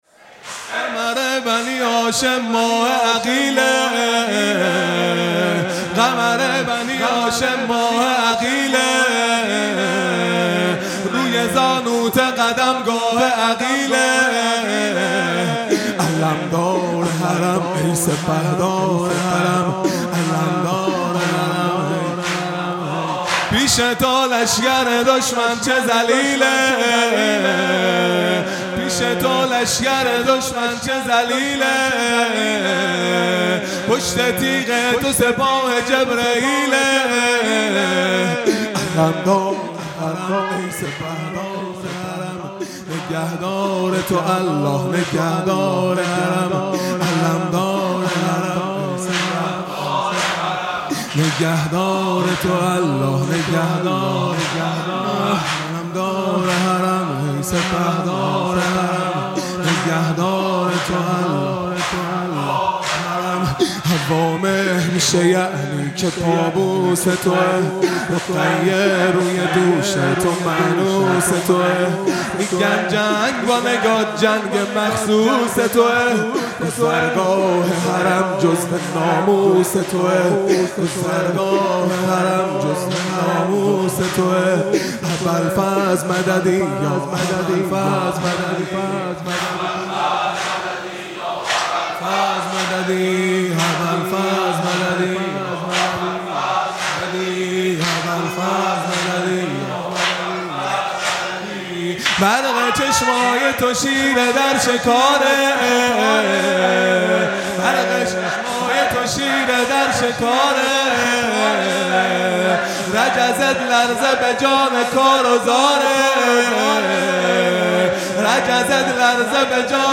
خیمه گاه - هیئت بچه های فاطمه (س) - زمینه | قمر بنی هاشم ماه عقیله | 15 مرداد ۱۴۰۱